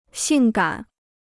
性感 (xìng gǎn): sexy; séduction.